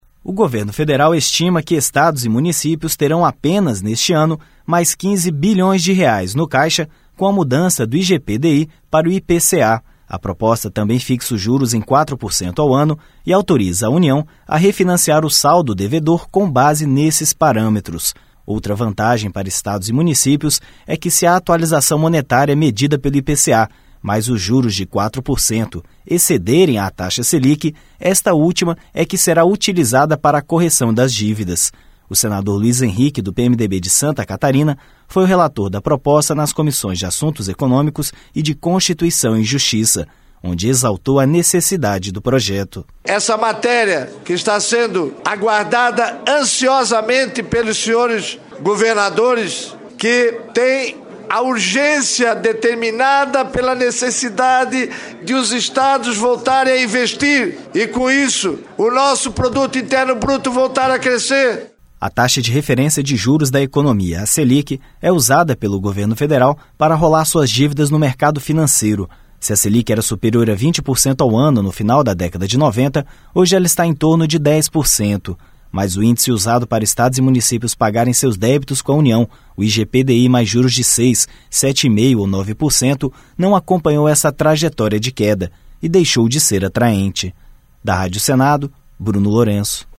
O senador Luiz Henrique, do PMDB de Santa Catarina, foi o relator da proposta nas comissões de Assuntos Econômicos e de Constituição e Justiça, onde exaltou a necessidade do projeto.